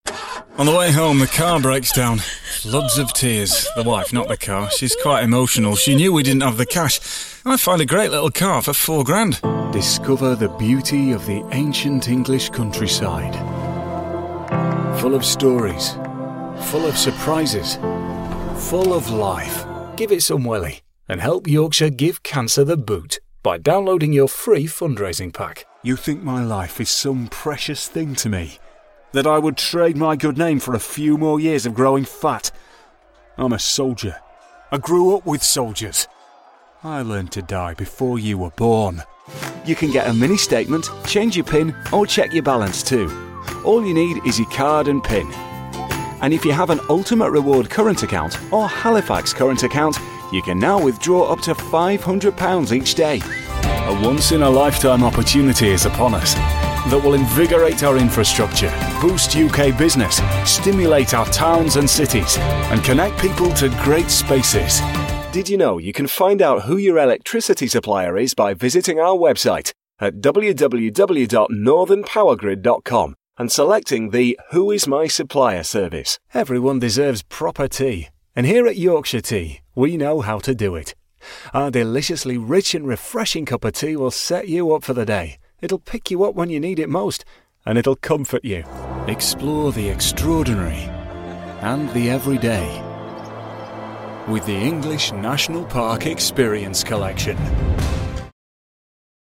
Northern Accent Showreel
Male
Neutral British
Yorkshire
Friendly
Reassuring
Confident